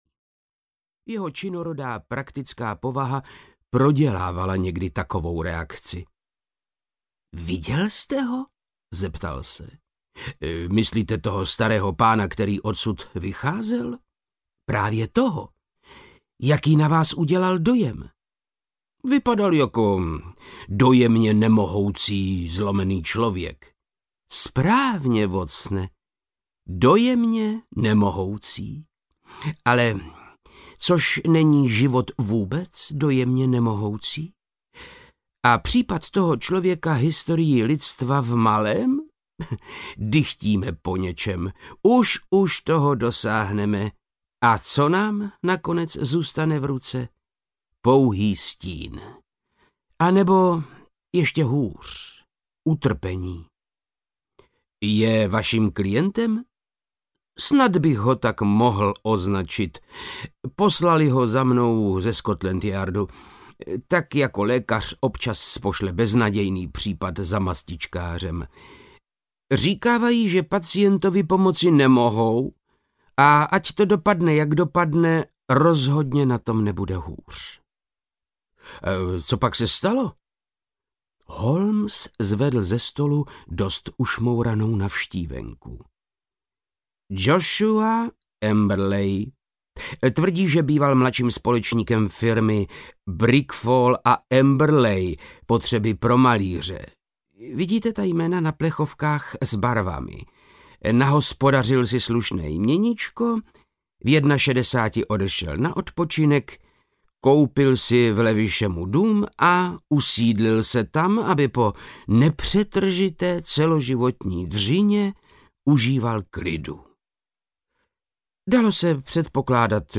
Barvíř na penzi audiokniha
Ukázka z knihy
• InterpretVáclav Knop